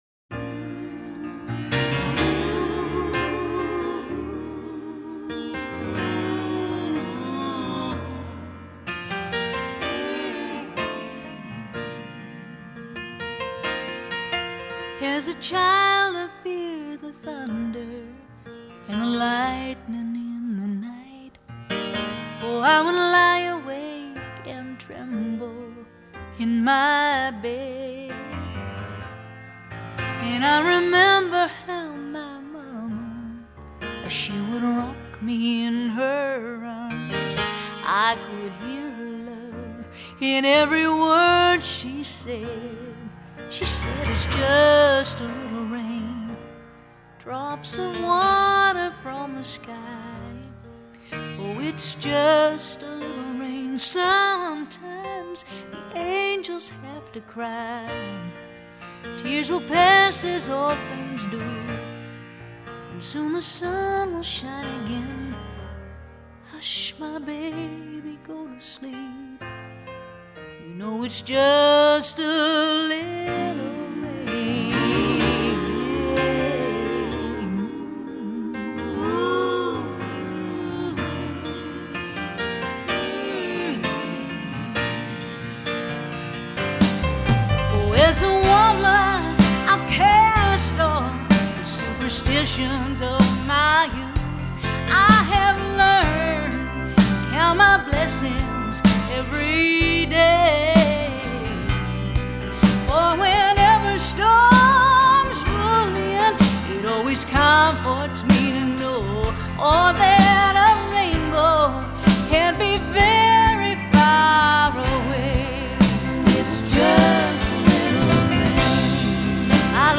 outstanding vocals